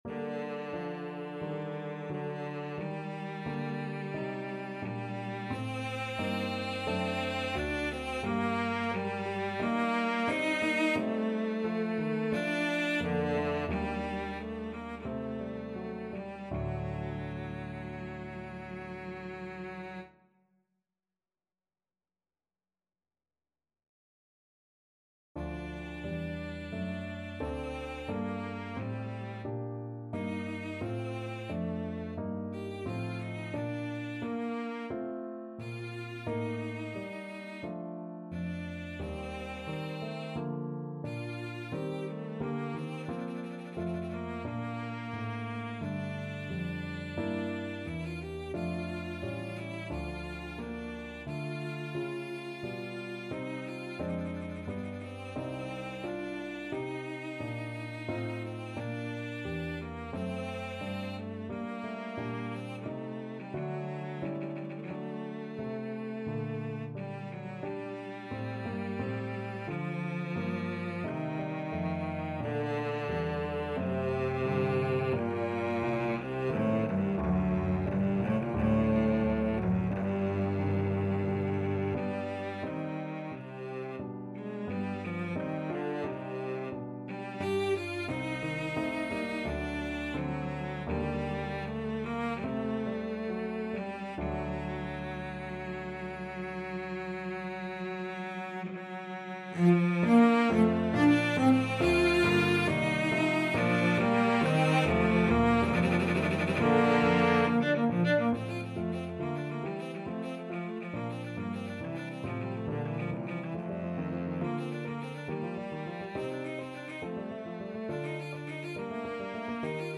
CelloPiano
Adagio =88
4/4 (View more 4/4 Music)
Cello  (View more Advanced Cello Music)
Classical (View more Classical Cello Music)